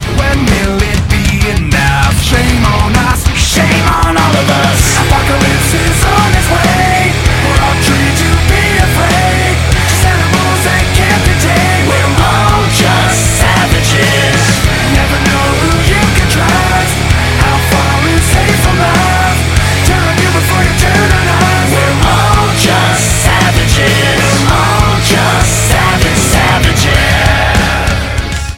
• Качество: 192, Stereo
красивая музыка